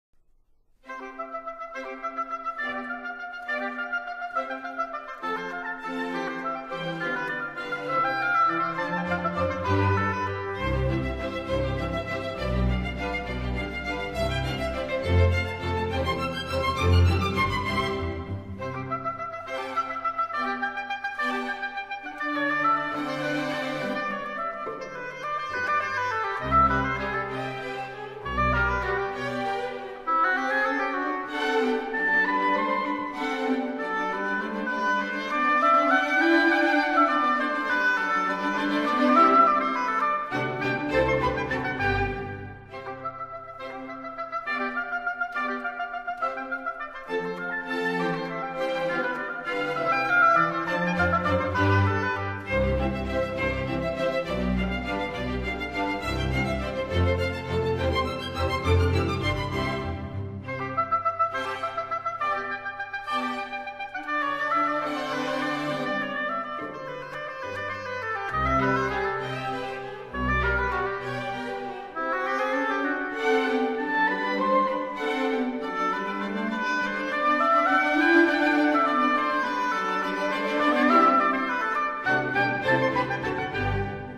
Música Domenico Cimarosa (Heinz Holliger)
*Domenico Cimarosa – Concierto para oboe, 4º Mov. Allegro Giusto
domenico-cimarosa-oboe-concerto-c-major-4-allegro-giusto-audiotrimmer-com.mp3